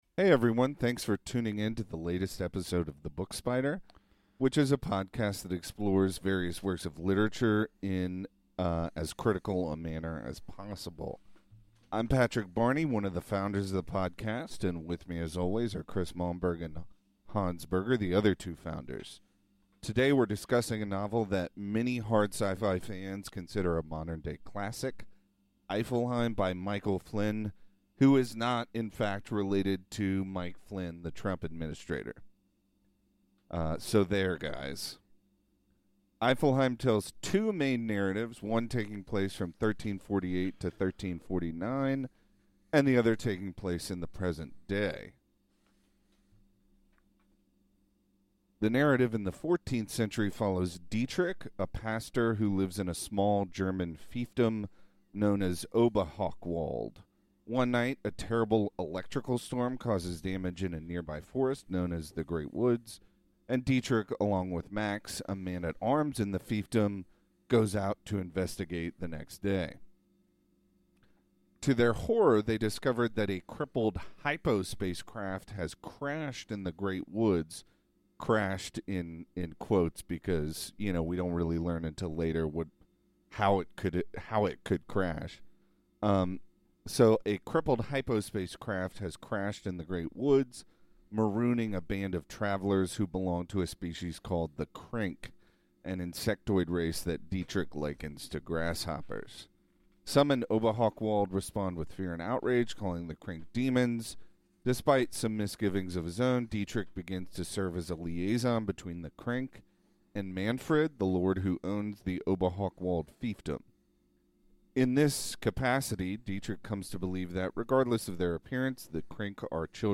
This episode, which discusses the modern-day sci-fi classic Eifelheim, features a rarity: all three podcasters agreeing on the quality of the text.